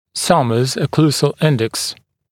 [‘sʌməz ə’kluːzl ‘ɪndeks][‘самэз э’клу:зл ‘индэкс]окклюзионный индекс Саммерса